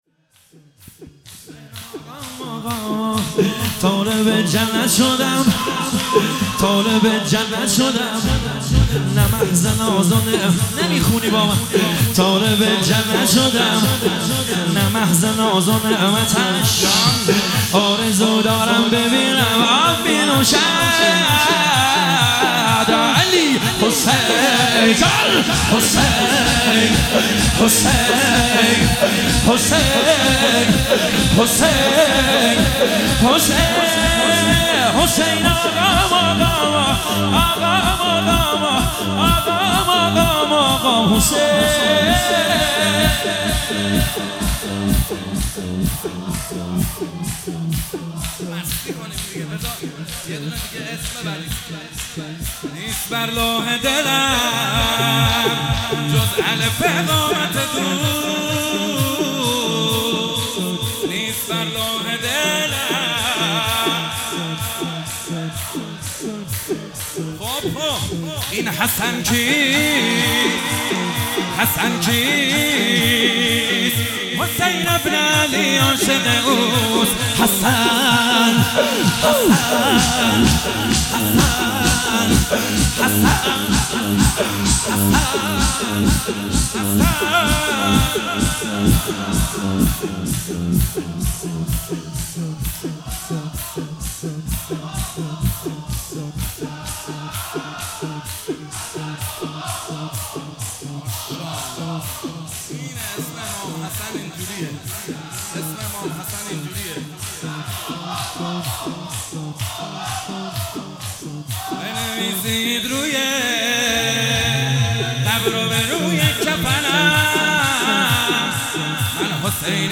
شور - مجلس روضه فاطمیه